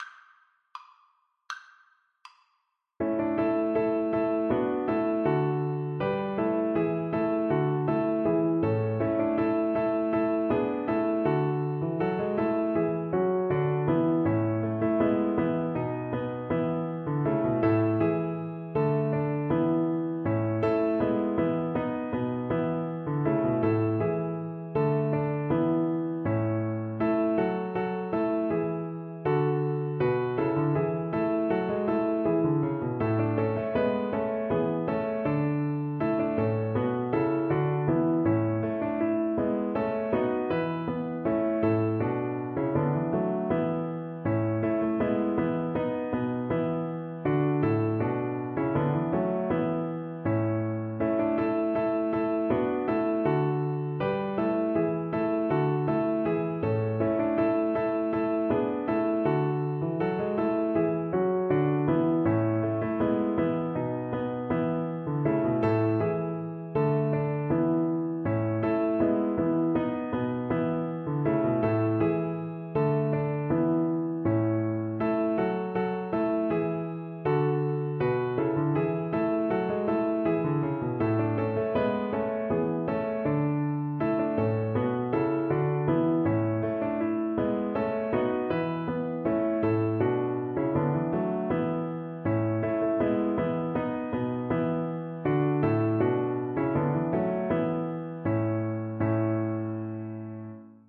Play (or use space bar on your keyboard) Pause Music Playalong - Piano Accompaniment Playalong Band Accompaniment not yet available transpose reset tempo print settings full screen
Violin
Two in a bar feel ( = c. 80)
D major (Sounding Pitch) (View more D major Music for Violin )
Classical (View more Classical Violin Music)